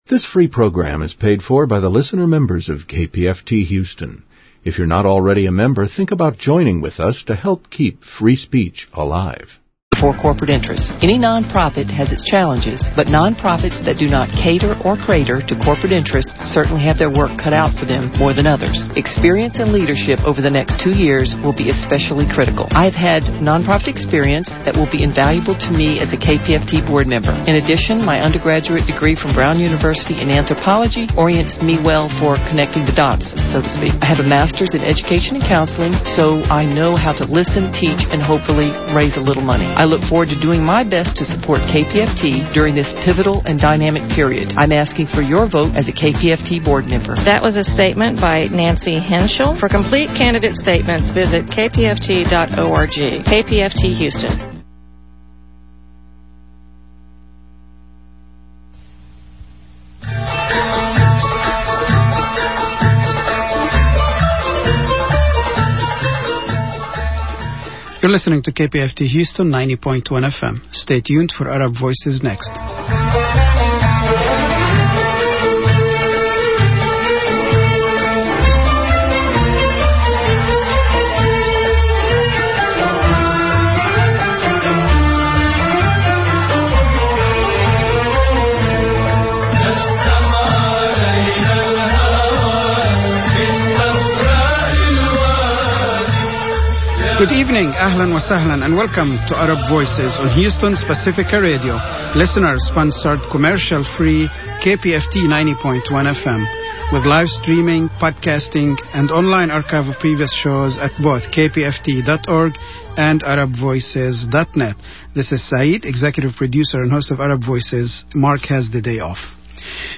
Arab Voices Radio Talk Show - 2009 Archives
Tune in and listen to Arab Voices for the latest news, views and live discussions about the Middle East.